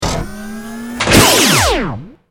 battlesuit_medlaser.wav